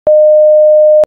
8 Ohms General Purpose Speaker 600mW 0Hz ~ 6kHz Top Round
Frequency (Hz + 20%)610
Sine-610Hz.mp3